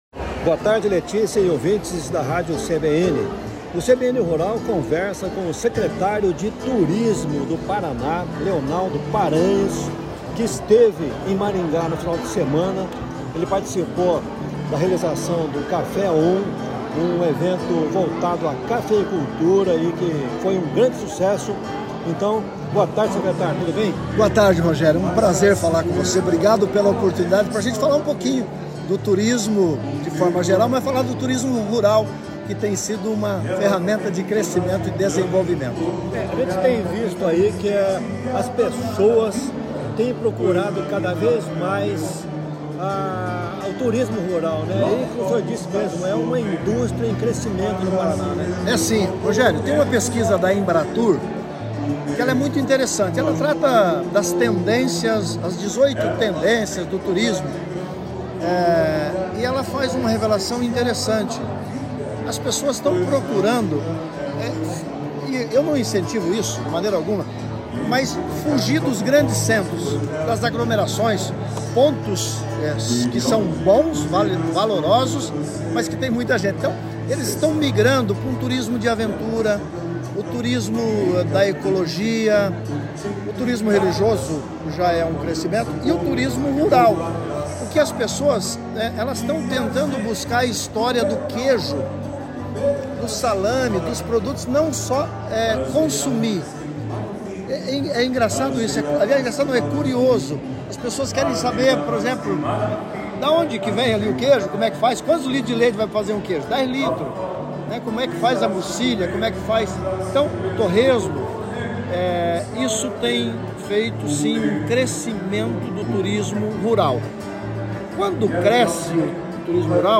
Em entrevista ao CBN Rural, o secretário de Esporte e Turismo do Paraná, Leonaldo Paranhos, destaca o crescimento do turismo rural no estado que apresenta um grande potencial nessa área, em diferentes vertentes, como o religioso, o gastronômico, o cultural, o de aventura, enfim. Mas, alerta: é preciso saber acolher o público, oferecendo um atendimento de qualidade e com profissionalismo.